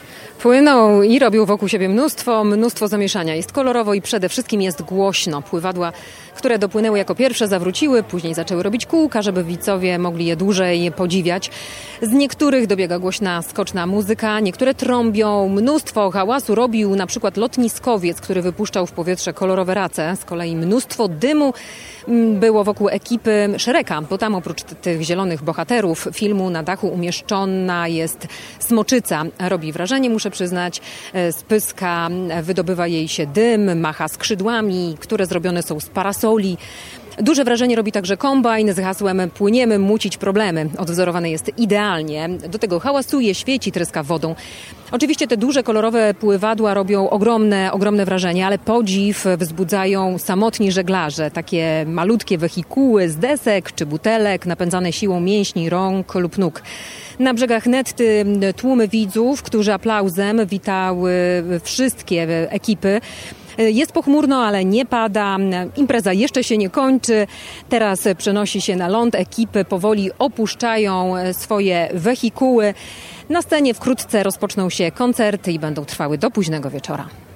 Trwają Mistrzostwa Polski w Pływaniu na Byle Czym - relacja